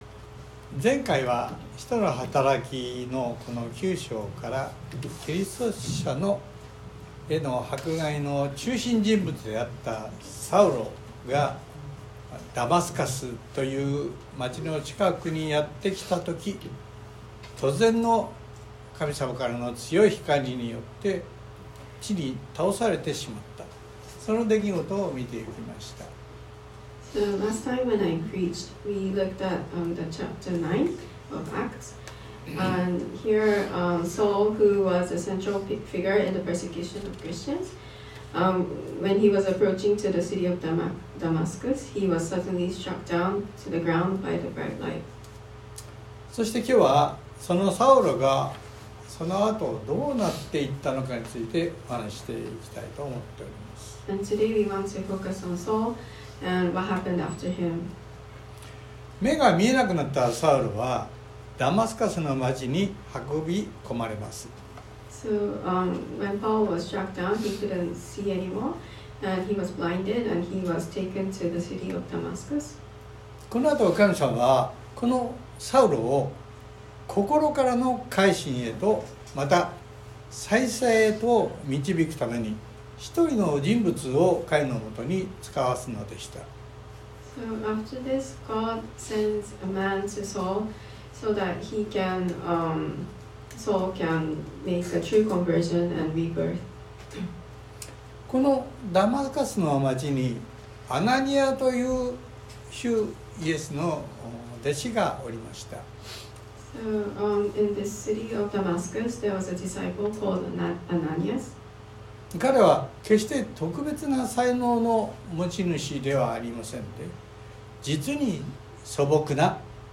↓Audio link to the sermon:(Sunday worship recording) (If you can’t listen on your iPhone, please update your iOS) Sorry, this post is no translate, only available in Japanese. 10 In Damascus there was a disciple named Ananias.